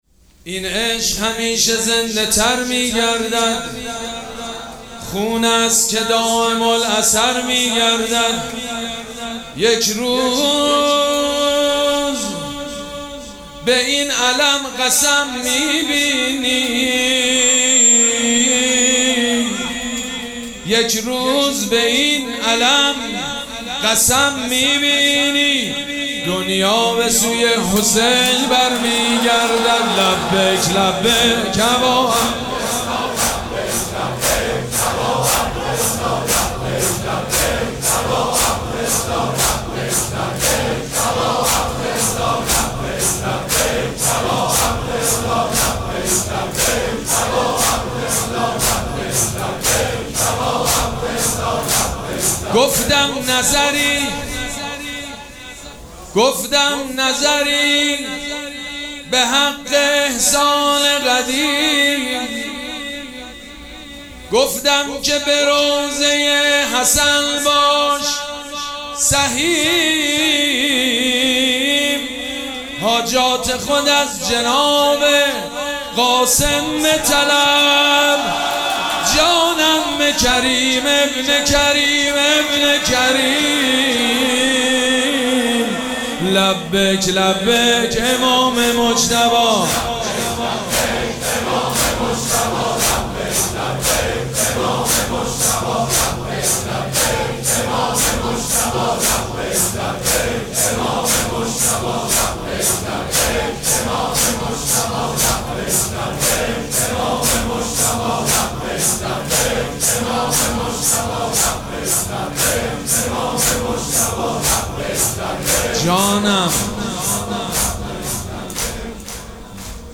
مراسم عزاداری شب ششم محرم الحرام ۱۴۴۷
مداح
حاج سید مجید بنی فاطمه